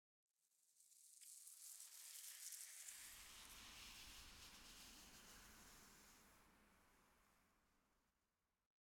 minecraft / sounds / block / sand / sand1.ogg
sand1.ogg